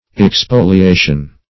Expoliation \Ex*po`li*a"tion\, n.